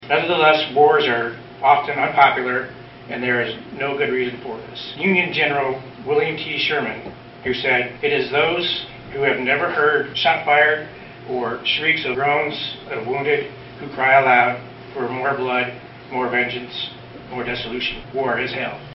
The ceremony, which was moved indoors due to rain Monday, honored all the fallen soldiers from all of the wars throughout this country’s history.